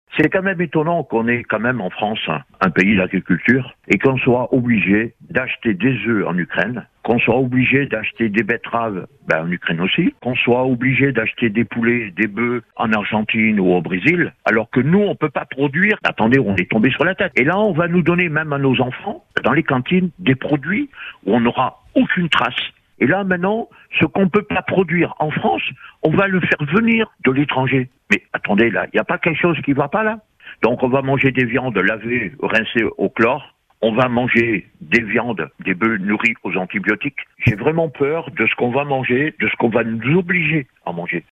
Ils étaient nombreux dans les rues de Paris.
Des agriculteurs en colère, venus dire stop à un accord qu’ils jugent dangereux, injuste et incohérent : le MERCOSUR.